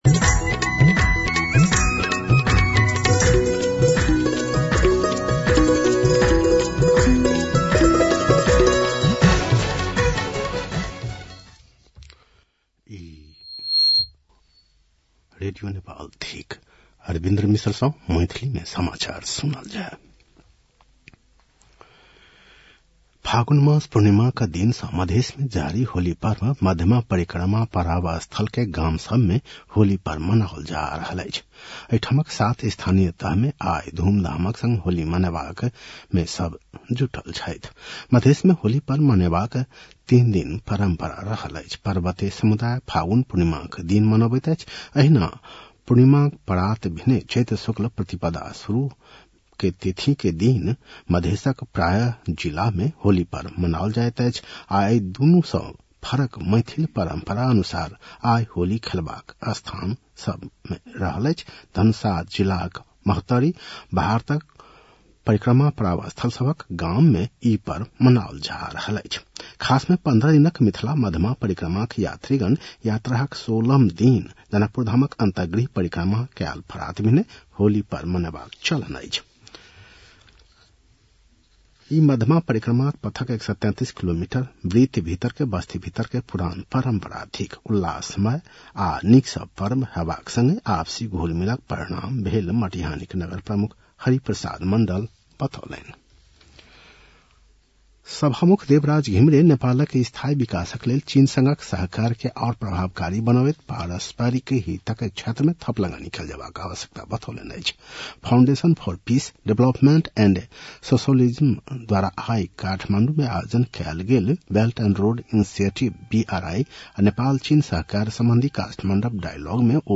मैथिली भाषामा समाचार : २ चैत , २०८१